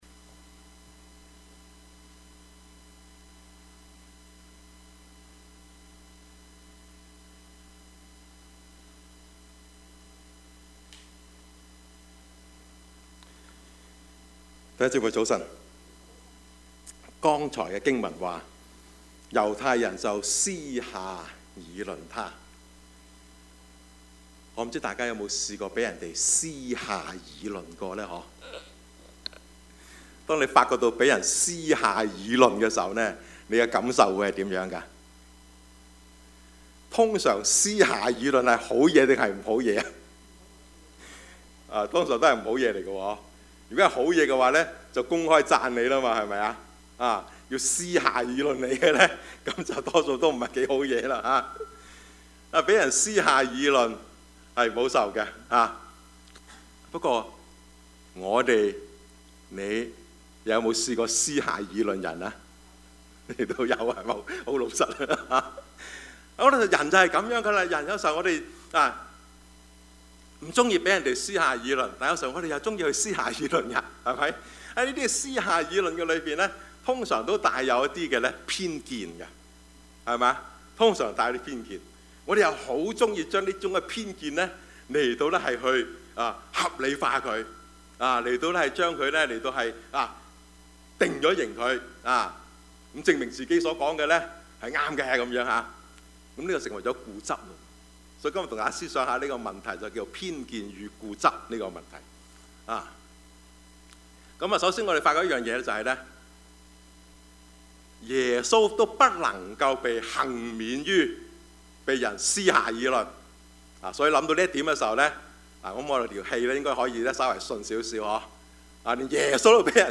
Service Type: 主日崇拜
Topics: 主日證道 « 吃教?信主?